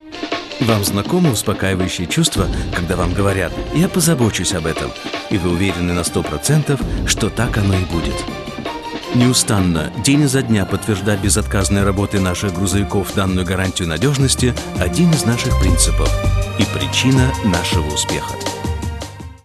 Sprecher russisch. Nativ Speaker. Stimmcharakter: werblich, freundlich, edel, dunkle Stimme,
Sprechprobe: eLearning (Muttersprache):